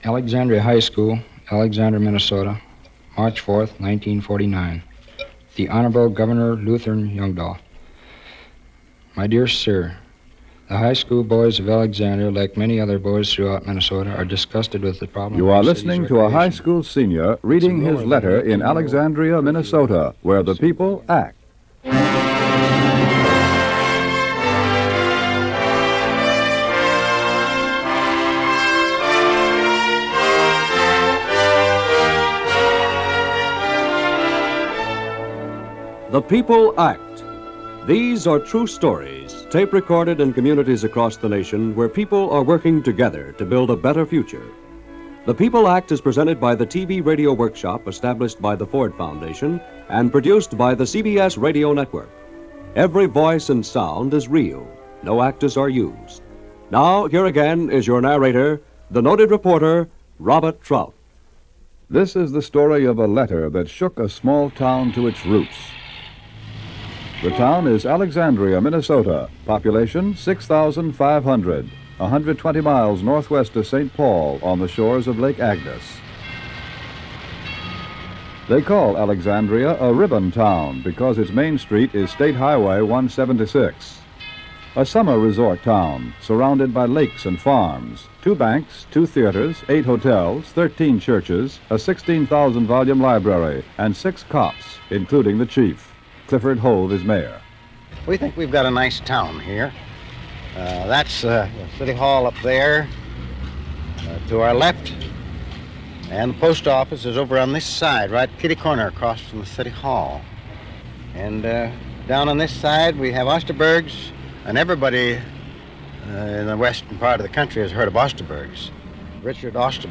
Continuing the survey of Radio Documentaries of the 1950s through 1970s.